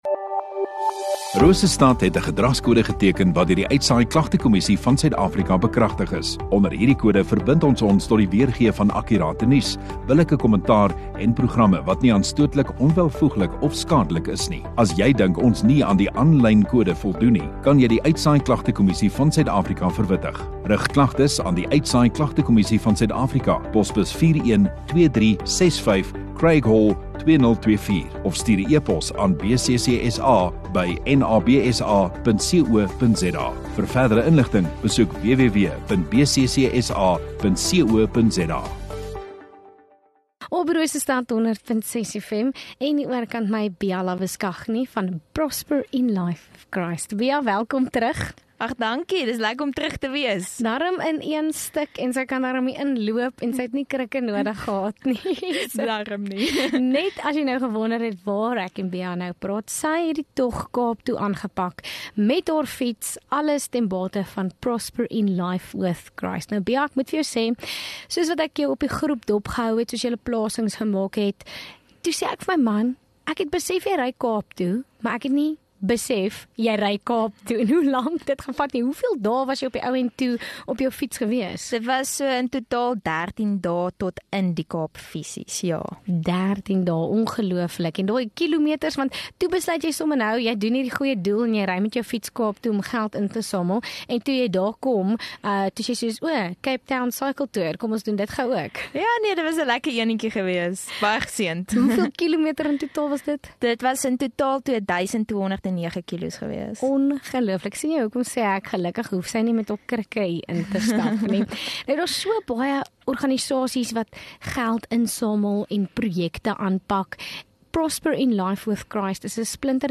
View Promo Continue Radio Rosestad Install Gemeenskap Onderhoude 24 Mar Prosper in Life with Christ